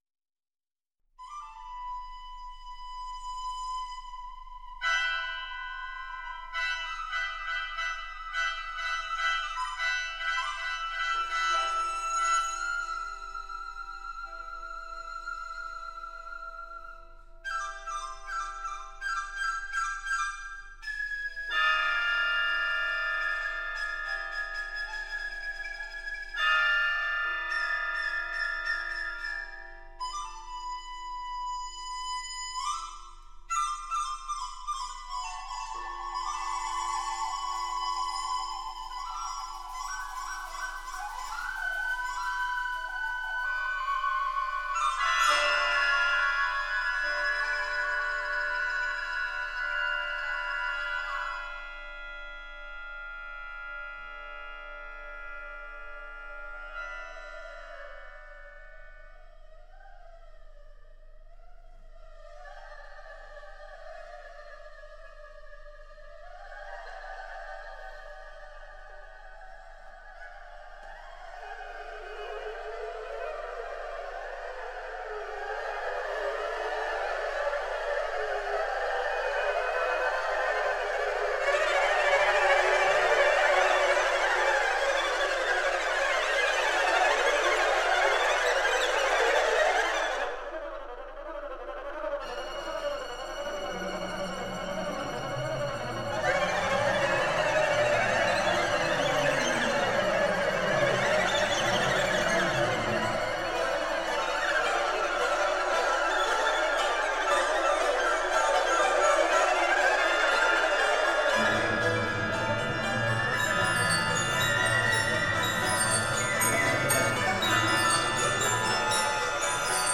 Symphonic Works for Chinese Folk Instrments